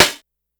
Snare_19.wav